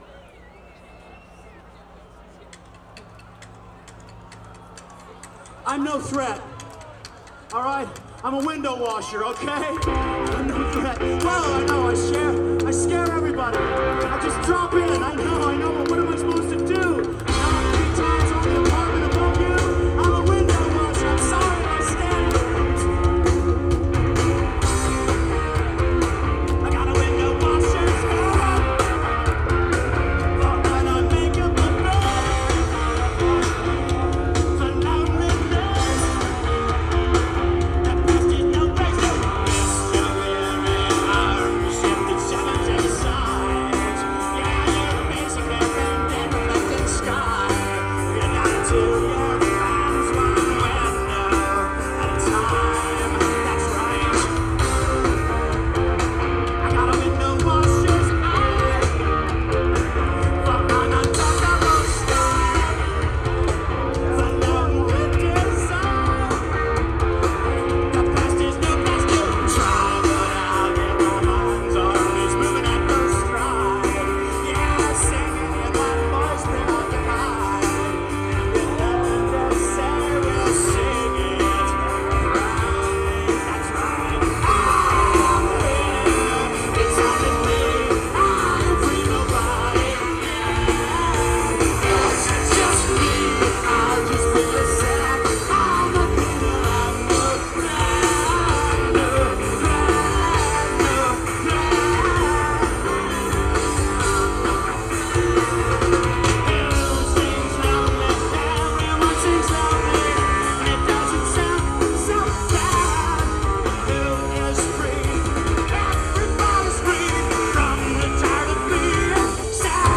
Source: Audience